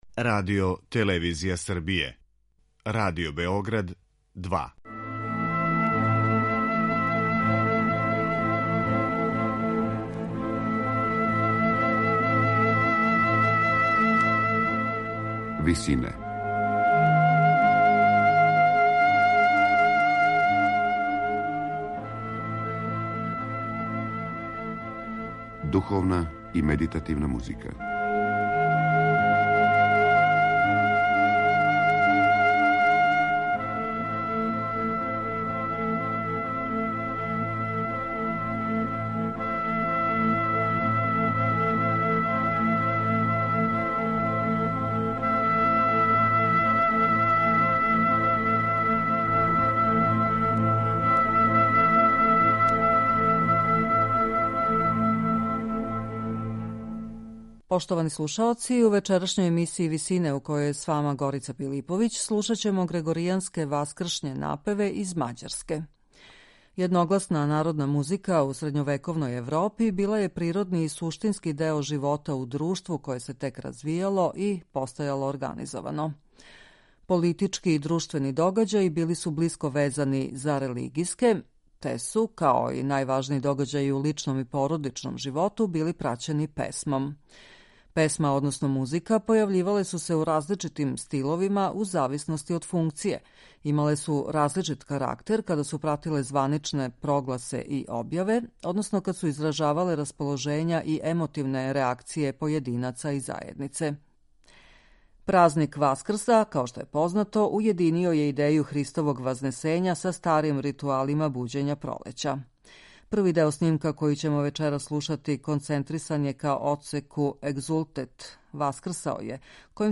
Ускршњи грегоријански корал
слушаћемо грегоријанске ускршње напеве из Мађарске.